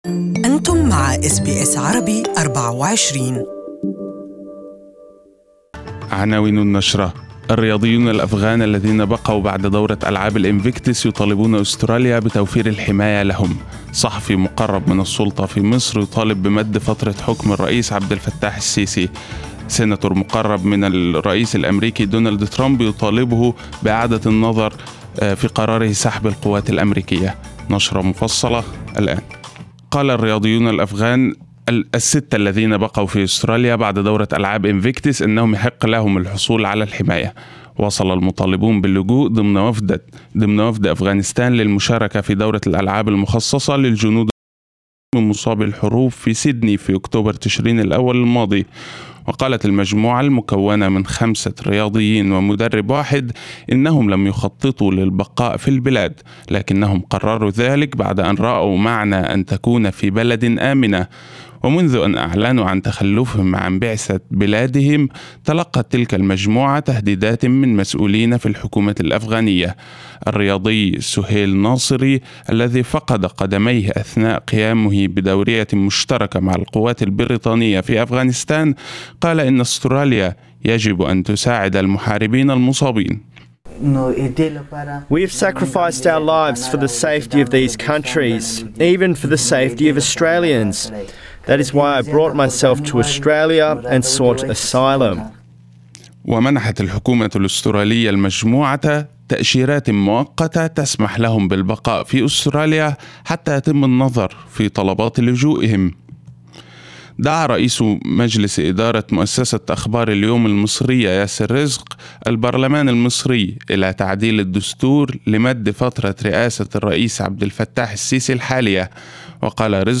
Morning news bulletin in Arabic.